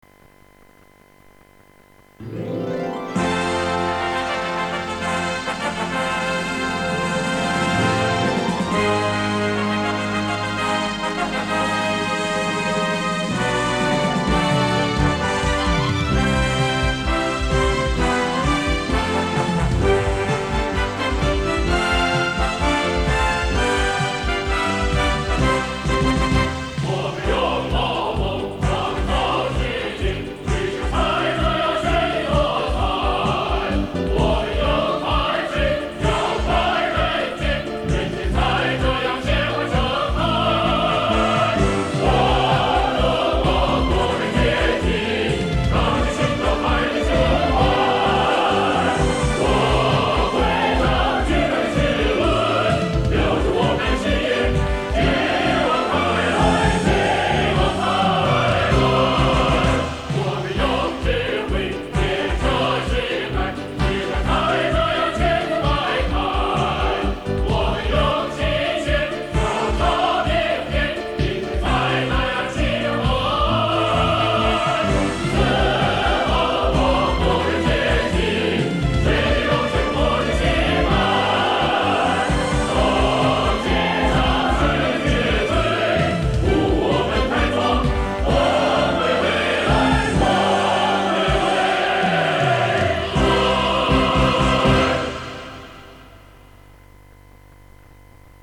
合唱